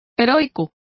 Complete with pronunciation of the translation of heroic.